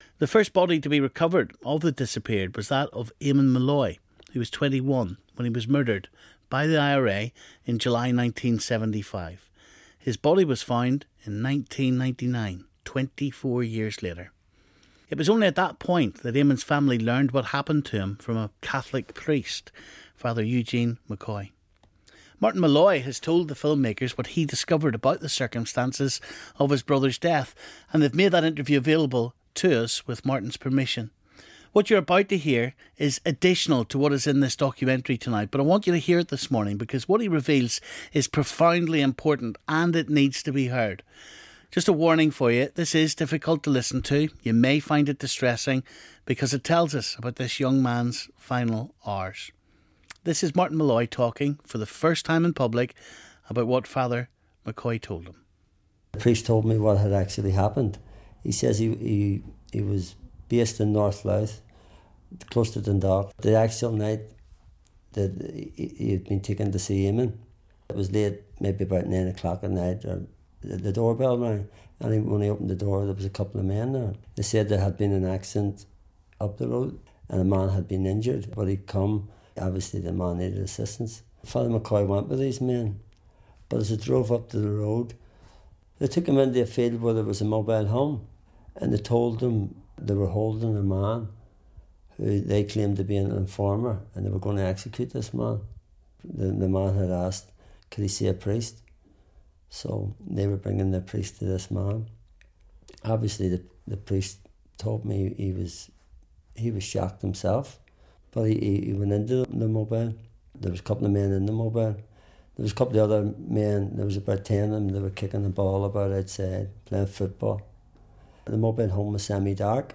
and they've made that interview available to us